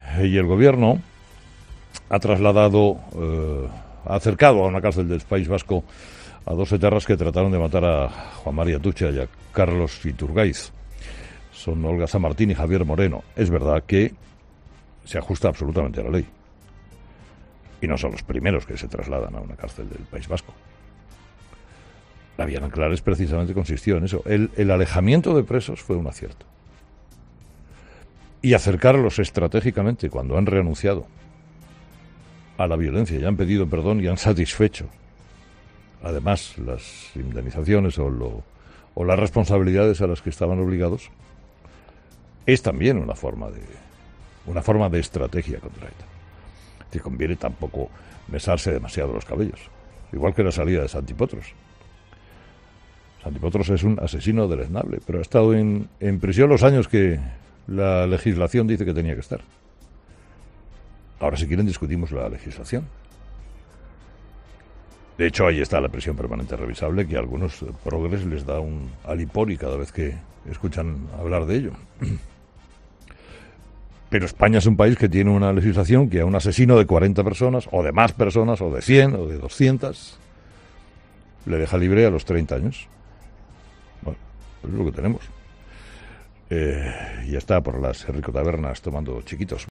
“Es verdad que se ajusta absolutamente a la ley”, ha comenzado diciendo Carlos Herrera en su monólogo de las 8h, ya que “no son los primeros que se trasladan a una cárcel del País Vasco”.